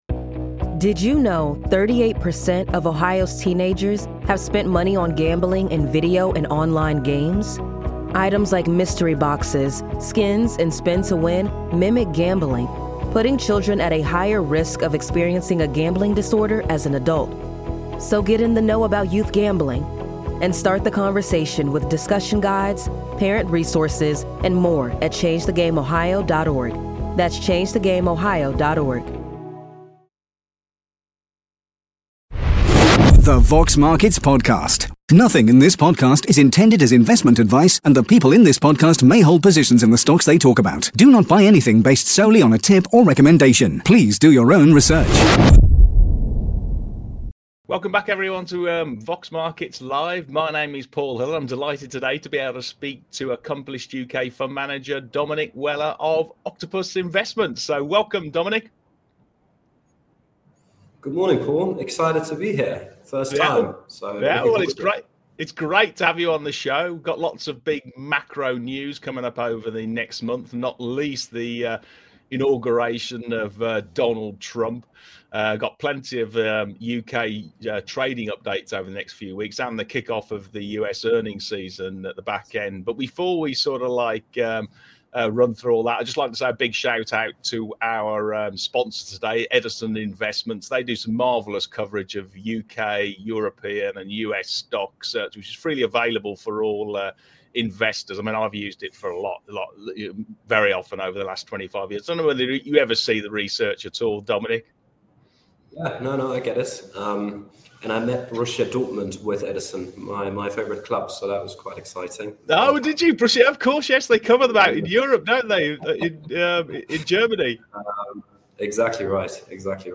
In this week’s live Exchange